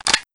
take_out_magazine.wav